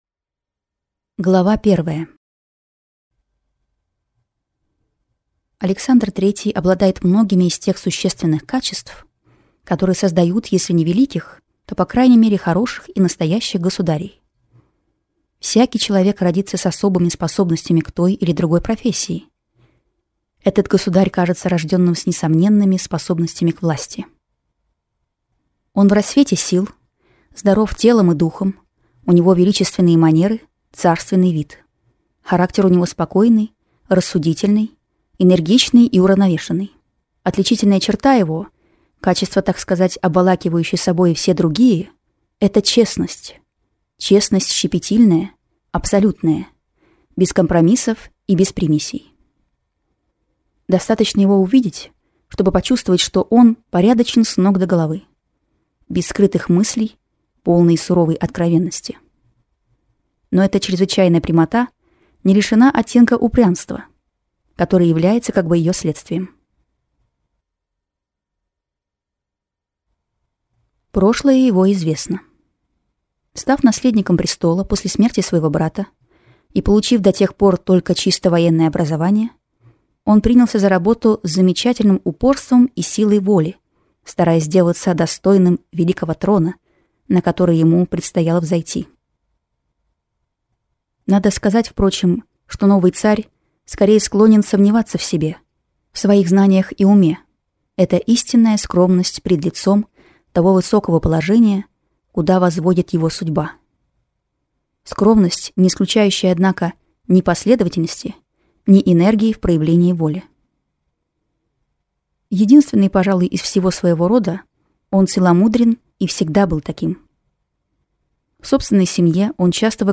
Аудиокнига Александр III | Библиотека аудиокниг
Читает аудиокнигу